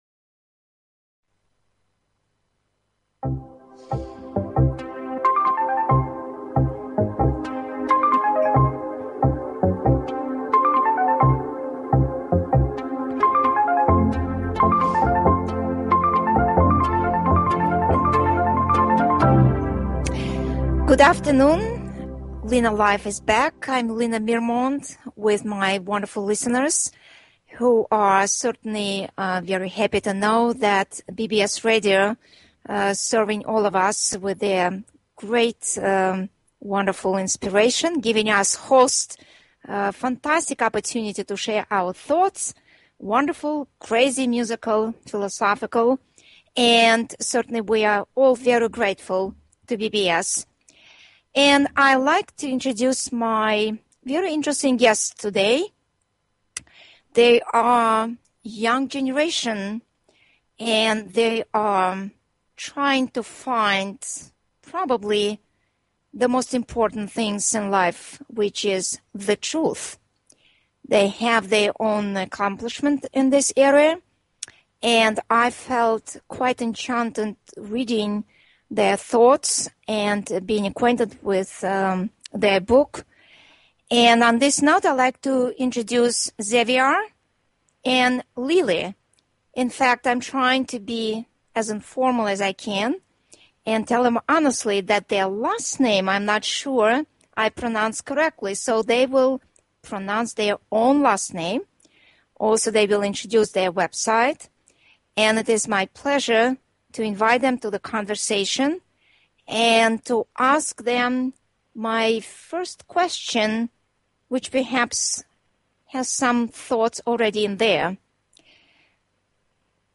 Piano Music
Talk Show